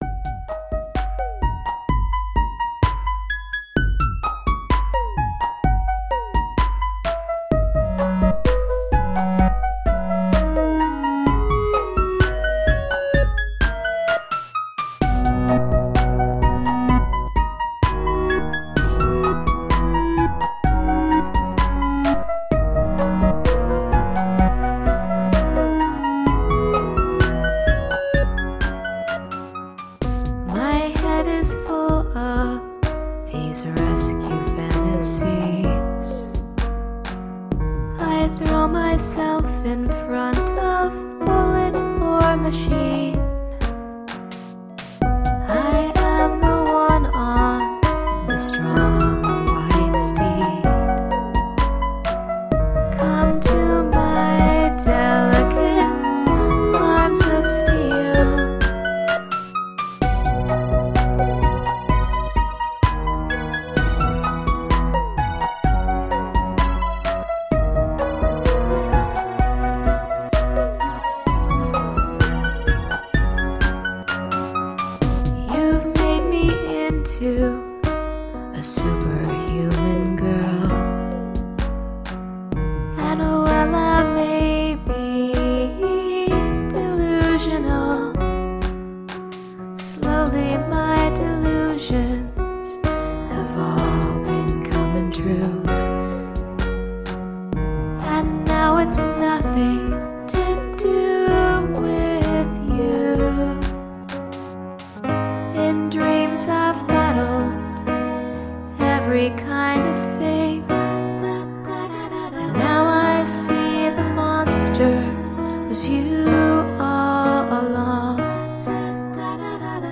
Lilting, textural alt-pop.
Tagged as: Alt Rock, Folk-Rock, Woman Singing Electro Pop